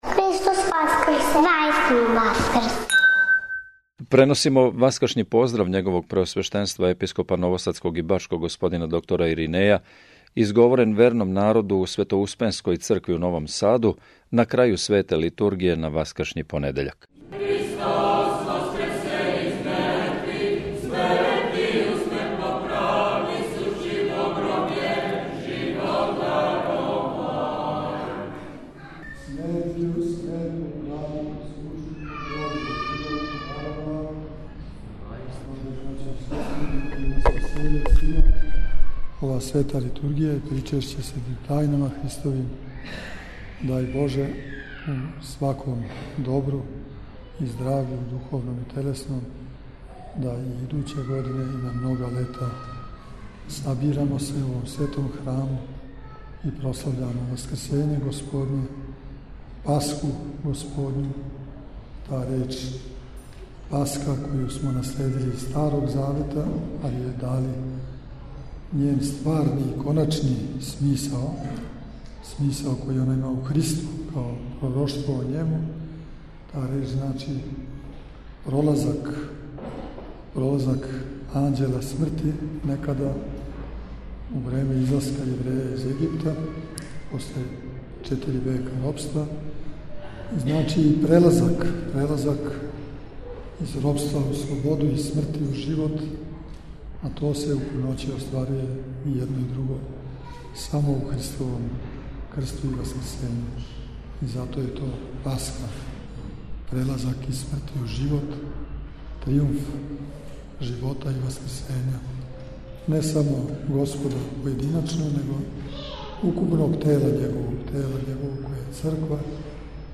Васкршњи понедељак у Светоуспенској цркви у Новом Саду
На крају свете Литургије, Владика Иринеј је упутио васкршњи поздрав присутном сабрању.